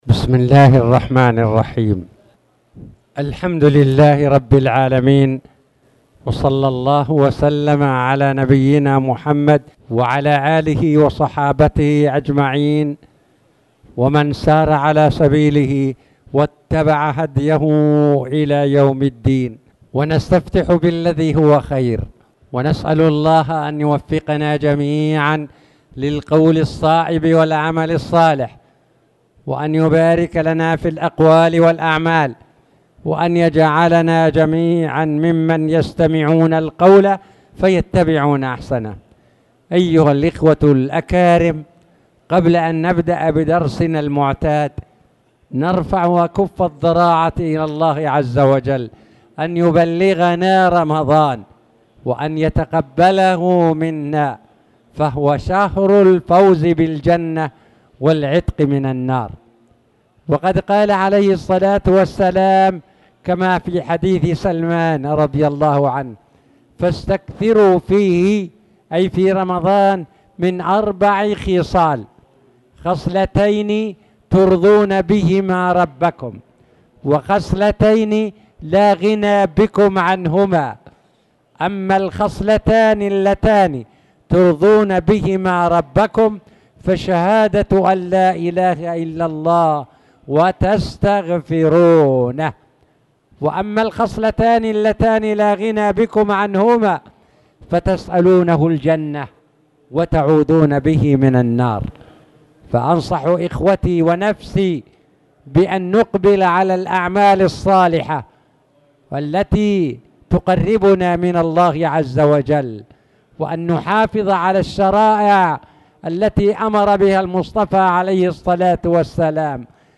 تاريخ النشر ٢٥ شعبان ١٤٣٨ هـ المكان: المسجد الحرام الشيخ